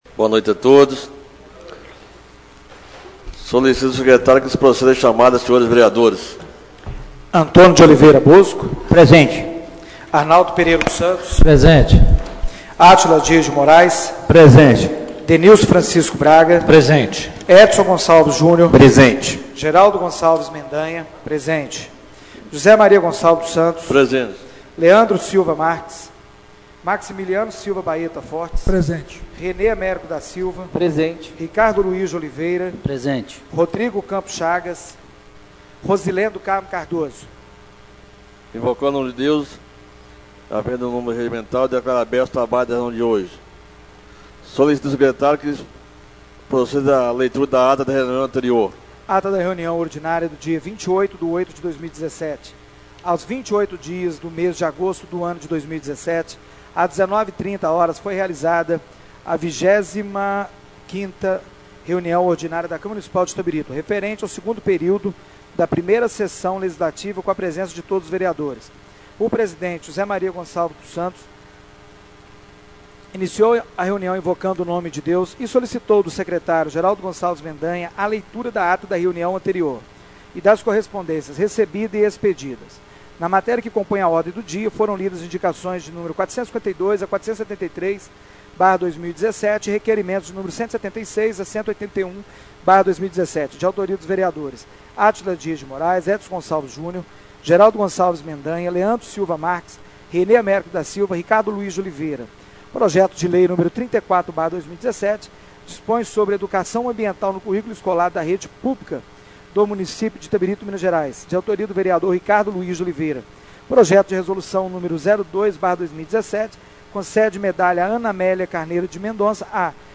Reunião Ordinária do dia 04/09/2017